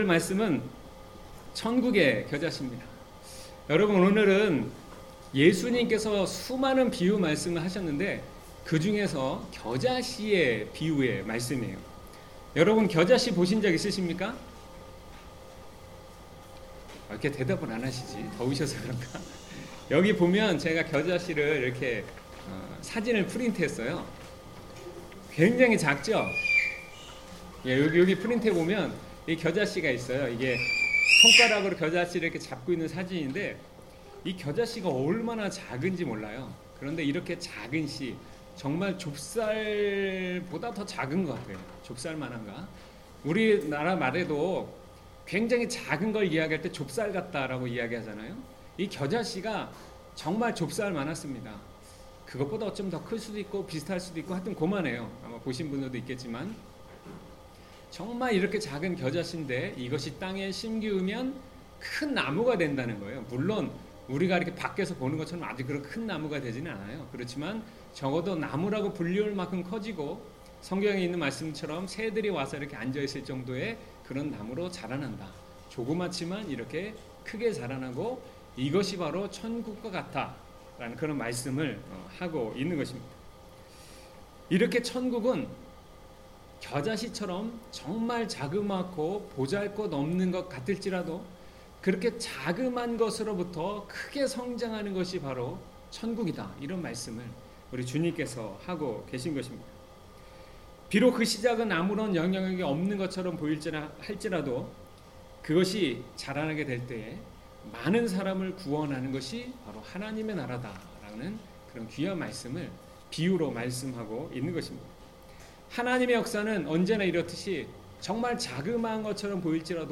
2019년 7월 21 주일 설교/ 천국의 겨자씨/ 막4:30-32